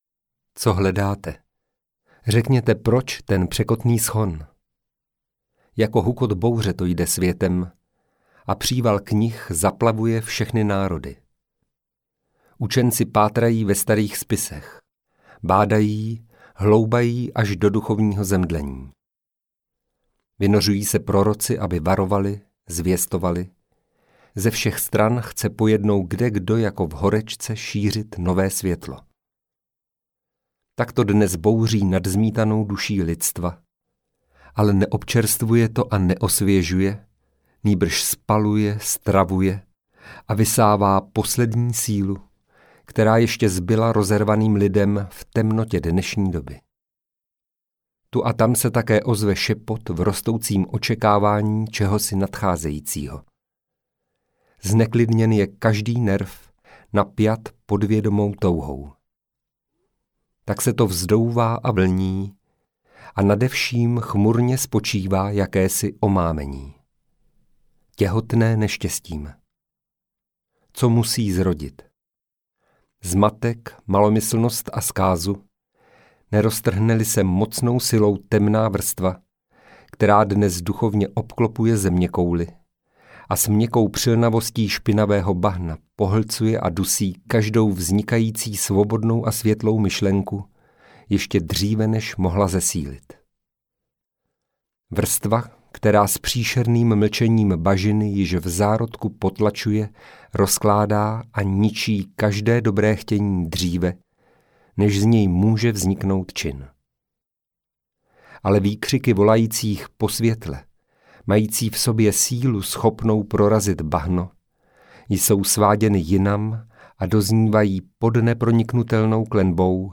Ve světle Pravdy audiokniha
Ukázka z knihy